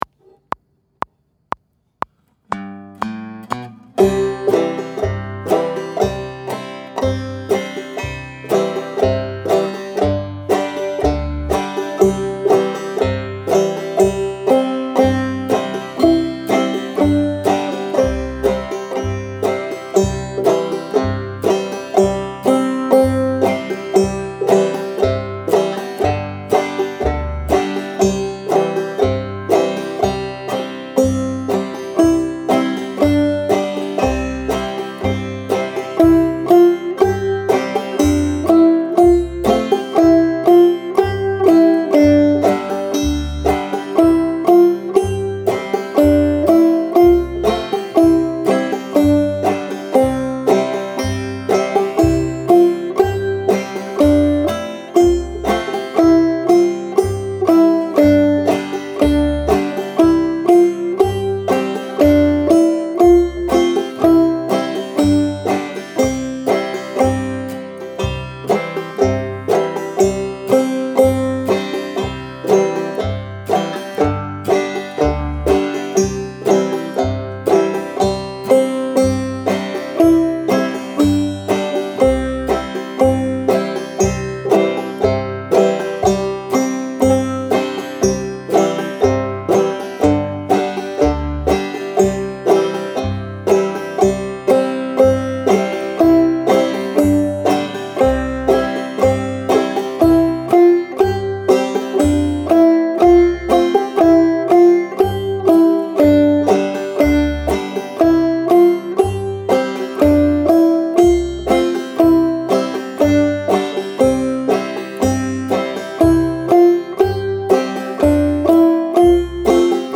Soldiers Joy Play Along Track – with banjo (C)
Soldiers-Joy-with-Banjo.mp3